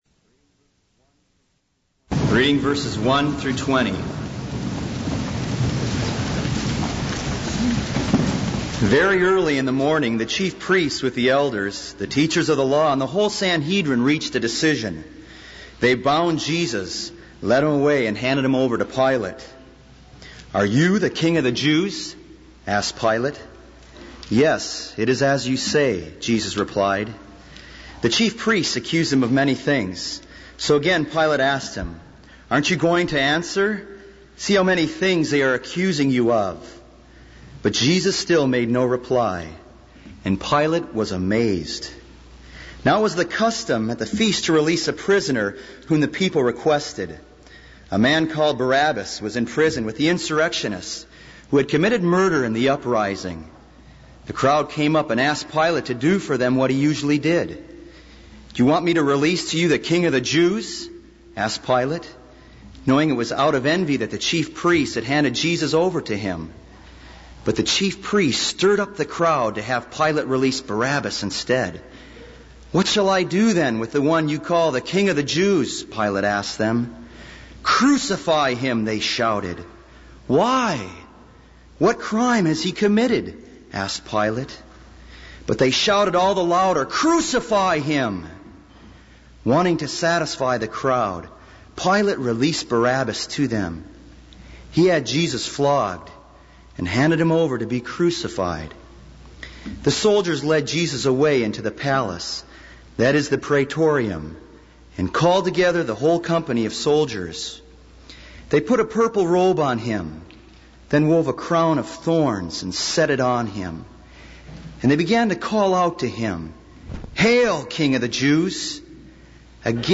This is a sermon on Mark 15:1-20.